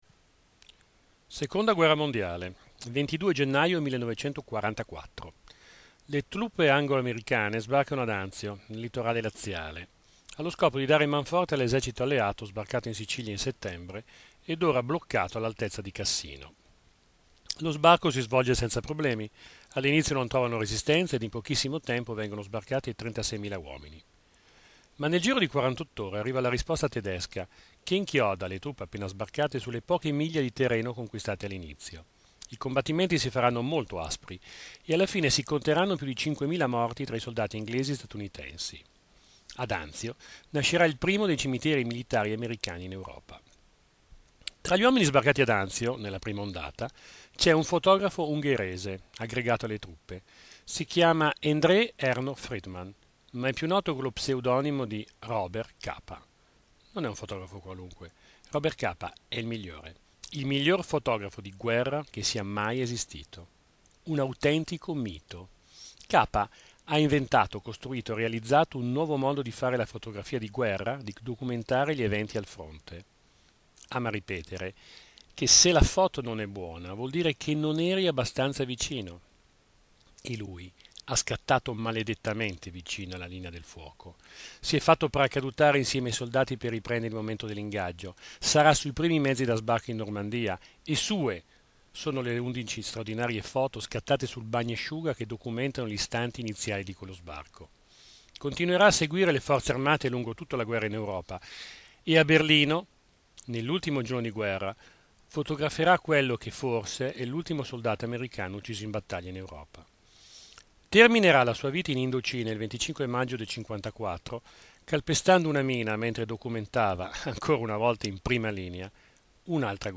Puntate della rubrica radiofonica l'Angolo di Orso Curioso trasmessa da Web Pieve Radio